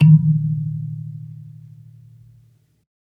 kalimba_bass-D#2-pp.wav